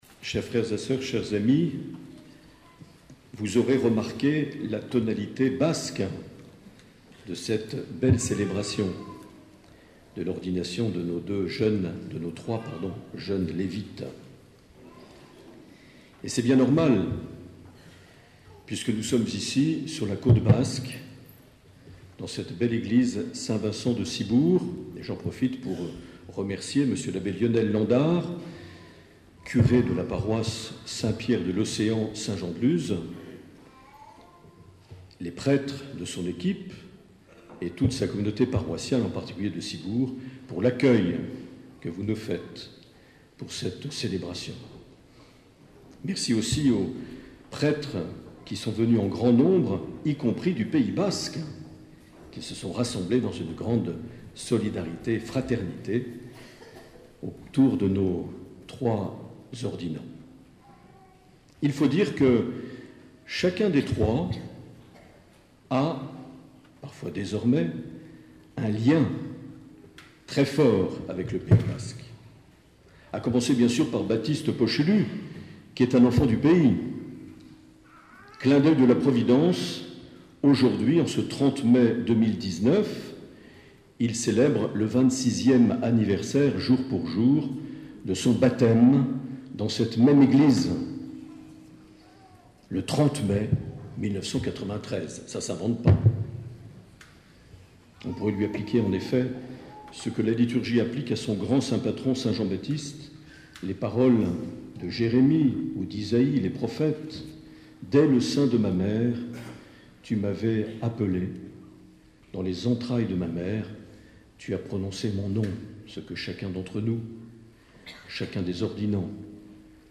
30 mai 2019 - Ciboure - Ordinations diaconales en vue du sacerdoce
Une émission présentée par Monseigneur Marc Aillet